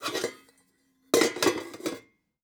SFX_Cooking_Pot_02.wav